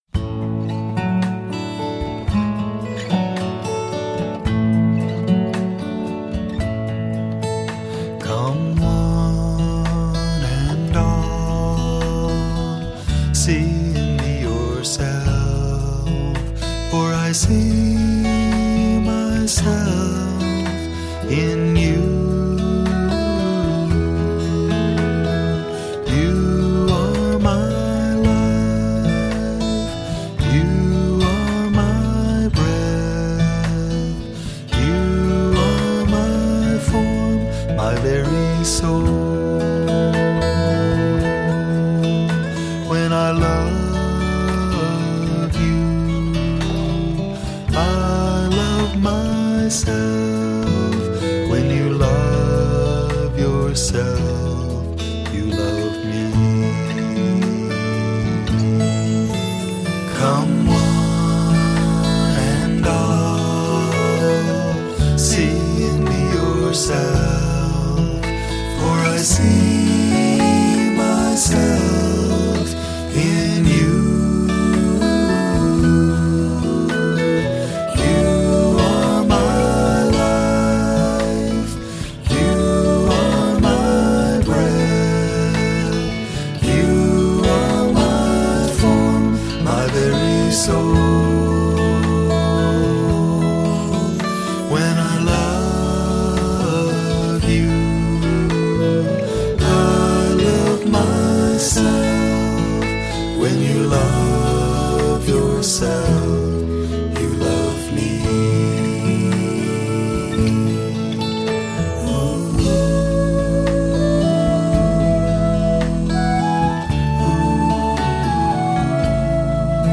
1. Devotional Songs
8 Beat / Keherwa / Adi
Medium Slow
Highest Note: S / C (higher octave)